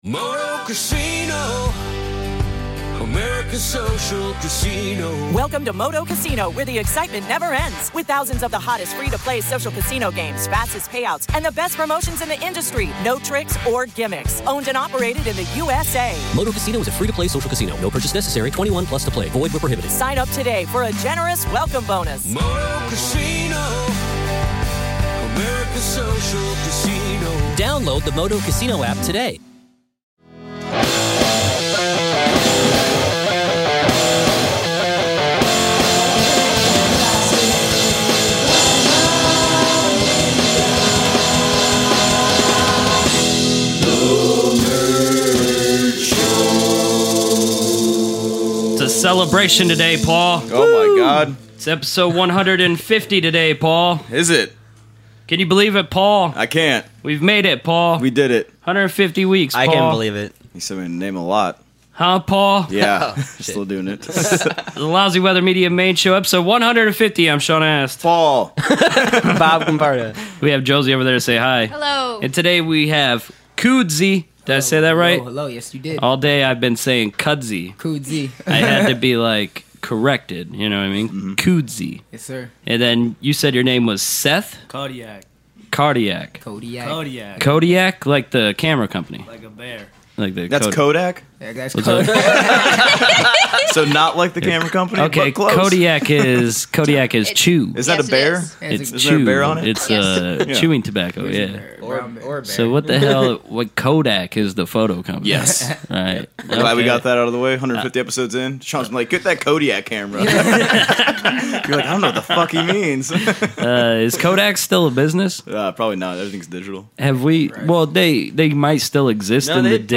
We also get a little freestyle in the last segment.